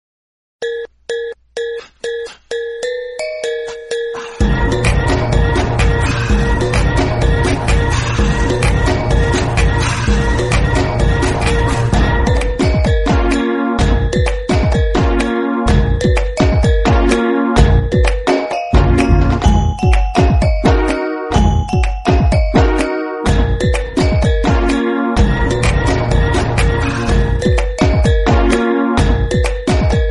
Category Instrumental